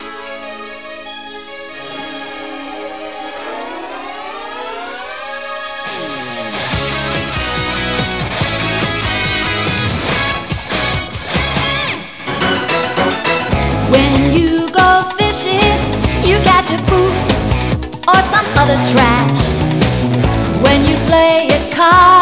TV Closing Theme